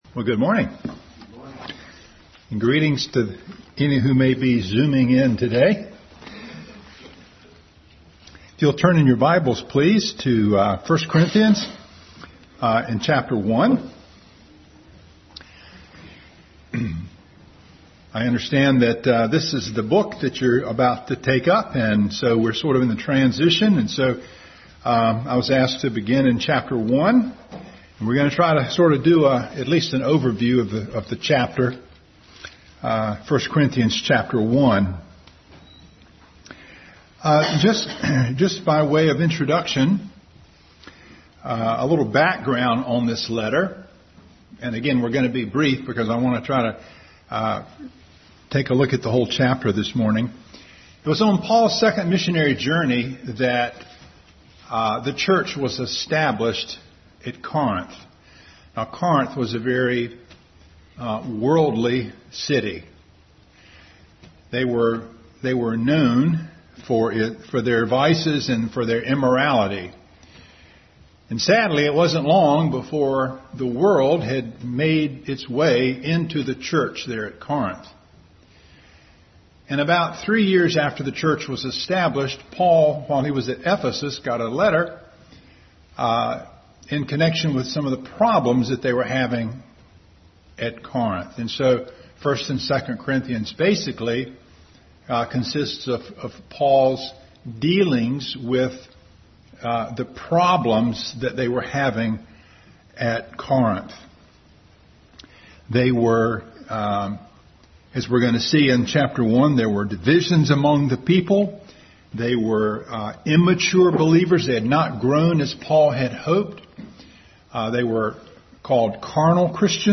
1 Corinthians 1:1-31 Service Type: Sunday School Bible Text